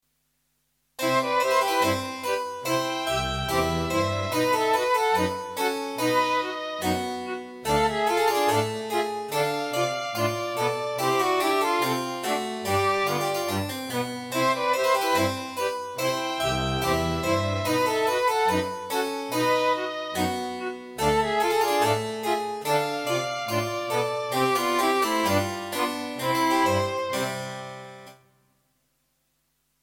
Een eenvoudige opzet van klavecimbel, fluit, cello, hobo, viool en altviool.
Ik hou van de rustieke, zondagse sfeer welke die muziek uit de recente oudheid uitstraalt.